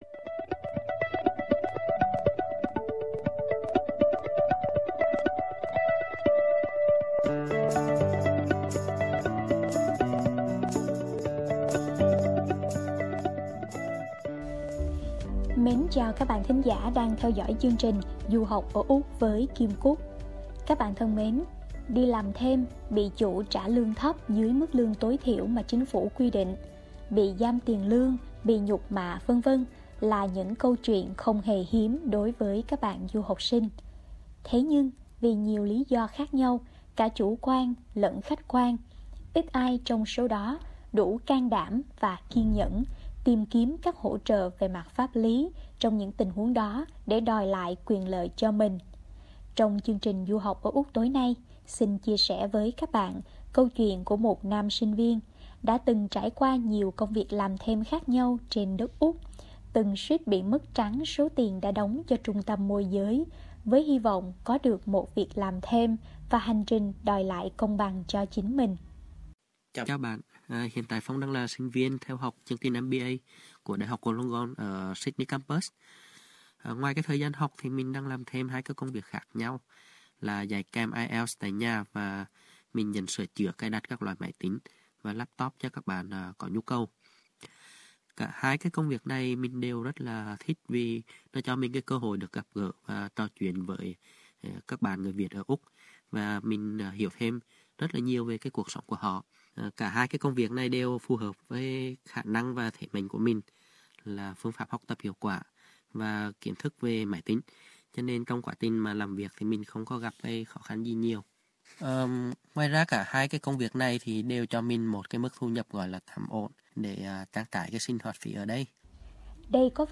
Dưới đây là câu chuyện của một nam sinh viên, đã từng trải qua nhiều công việc làm thêm khác nhau trên đất Úc, từng suýt bị mất trắng số tiền đã đóng cho trung tâm môi giới với hy vọng có được một việc làm thêm, và hành trình đòi lại công bằng cho chính mình.